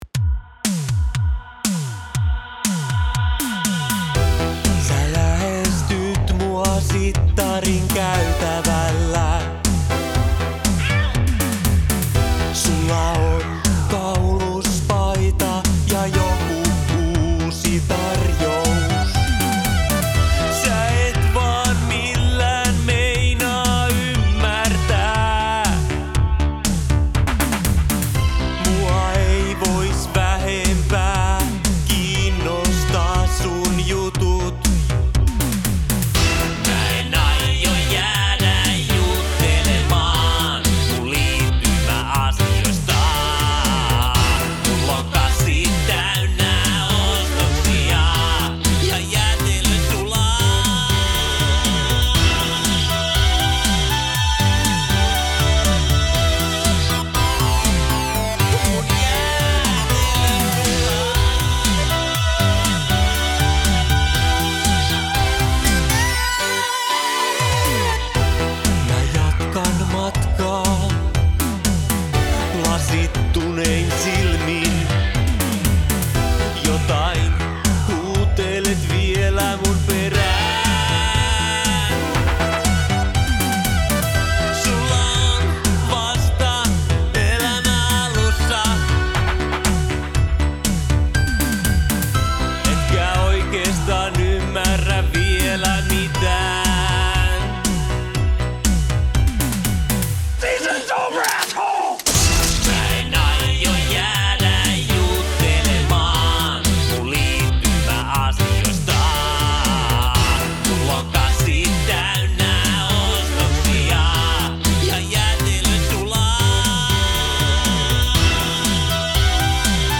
Musiikki
Logic, Bass Station 2, Blofeld, minilogue xd.